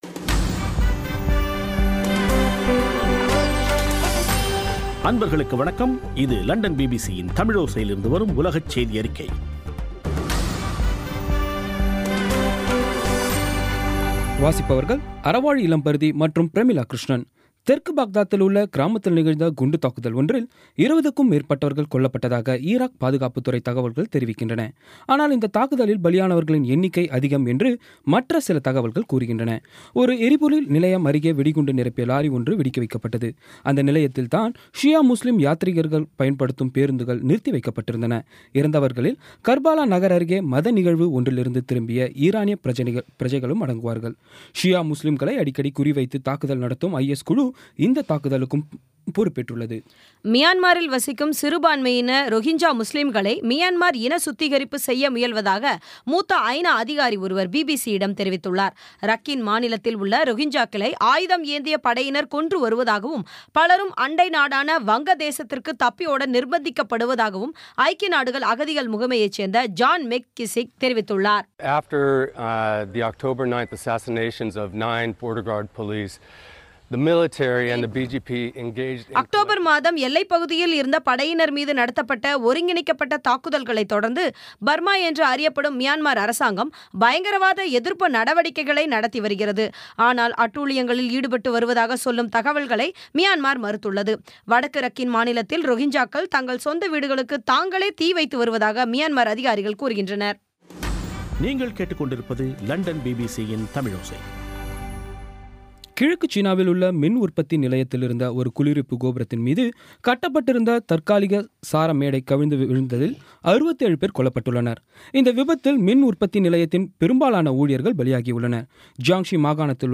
பி பி சி தமிழோசை செய்தியறிக்கை (24/11/16)